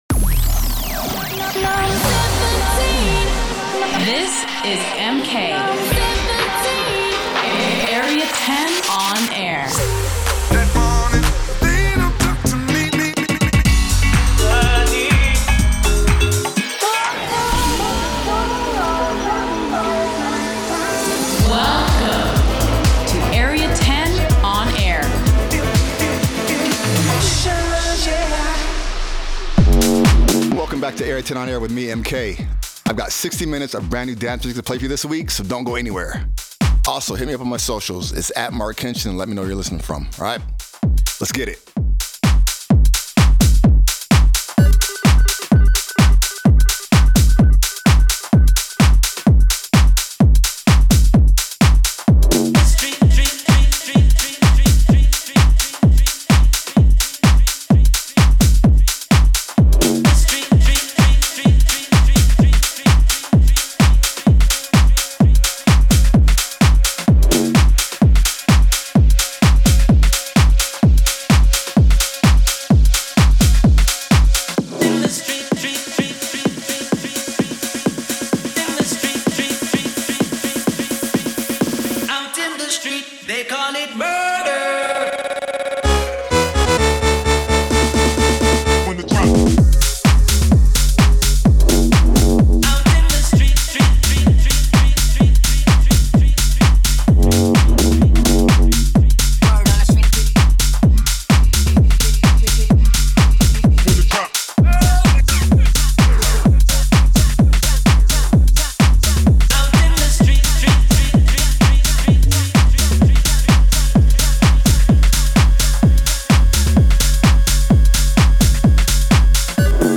Delving in to house and techno, new and old